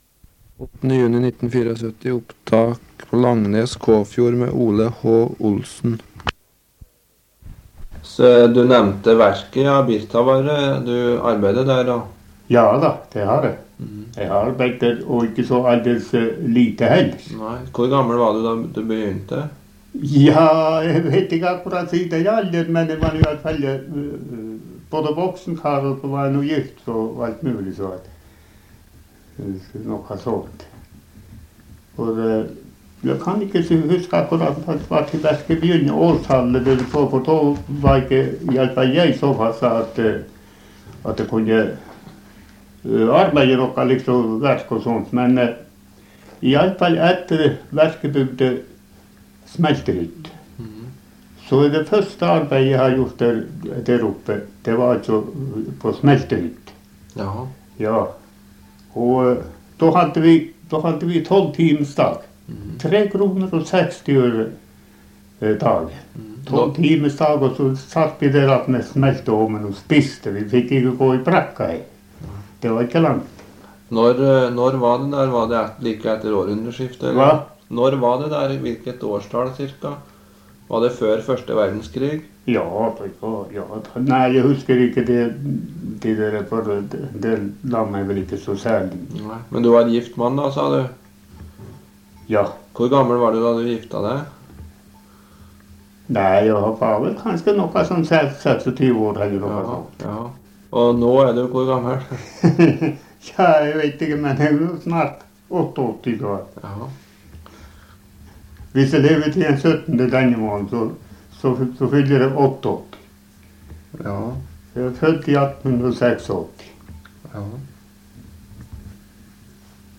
Kildetype Lydopptak
Sted: Kåfjord, Langnes
Sted: Kåfjord, Manndalen